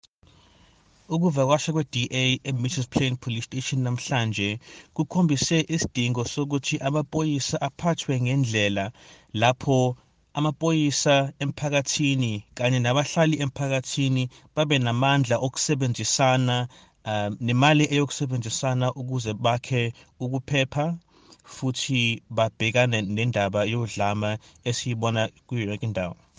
Issued by Zakhele Mbhele MP – DA Shadow Minister of Police